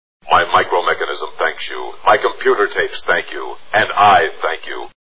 Lost in Space TV Show Sound Bites